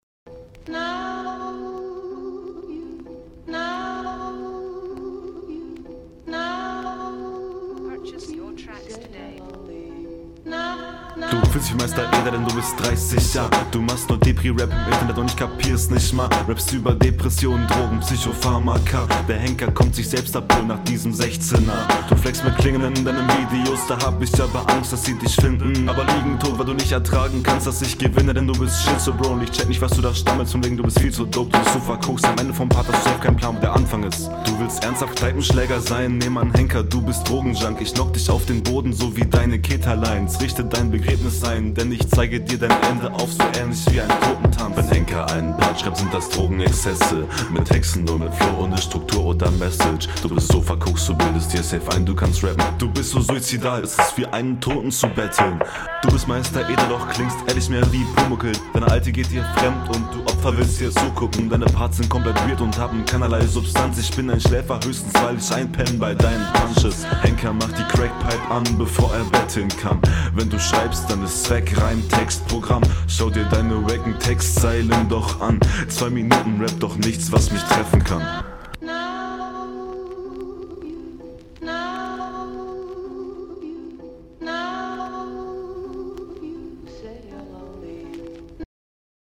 Stimme relativ lustlos und drucklos.